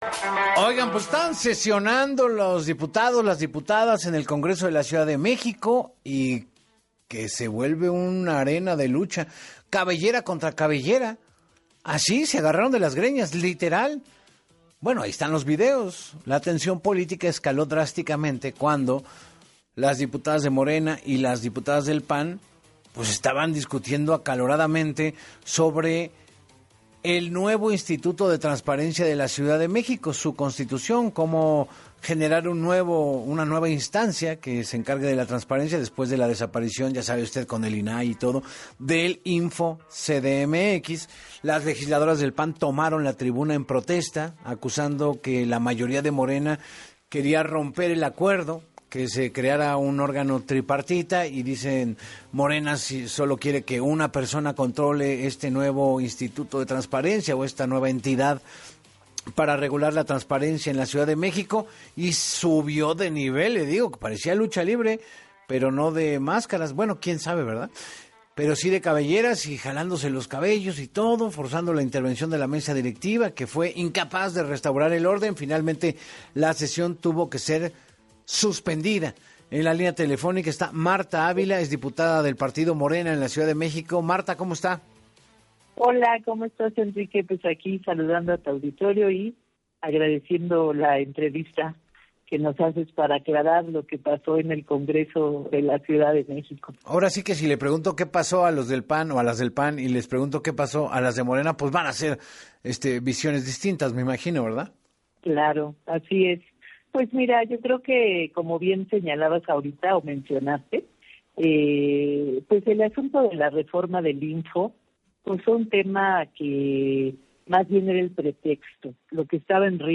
En entrevista para ‘Así Las Cosas con Enrique Hernández Alcázar’, la diputada de Morena, Marta Ávila, narró los hechos que llevaron a la suspensión momentánea de la sesión y acusó a la bancada panista de intentar reventar la aprobación del Paquete Fiscal bajo el pretexto de defender la transparencia.